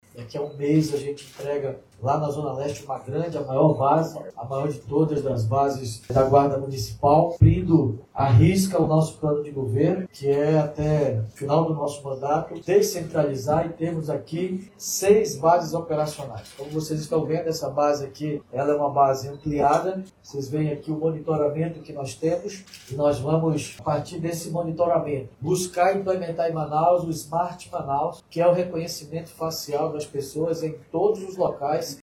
Com a nova estrutura, a guarda poderá atuar de forma ágil e eficiente nas ações de patrulhamento e proteção ao patrimônio público, afirma o Prefeito de Manaus, David Almeida.